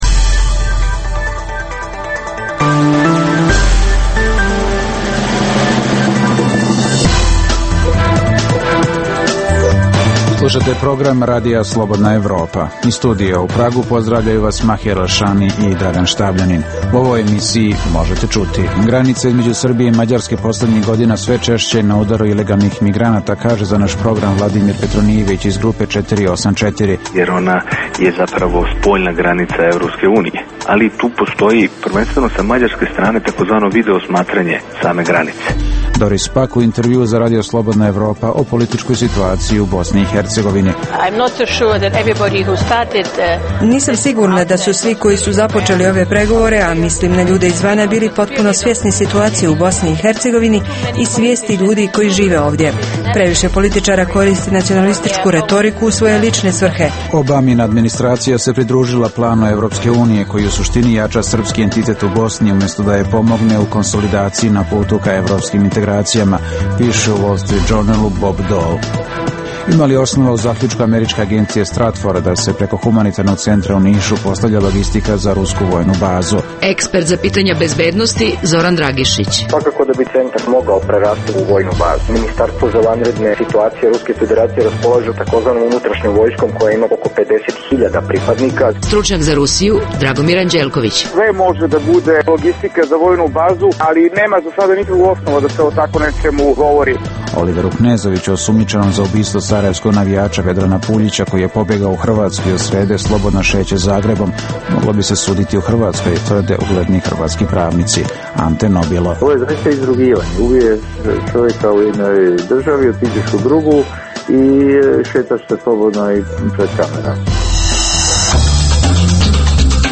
Doris Pak u intervjuu za Radio Slobodna Evropa o političkoj situaciji u BiH.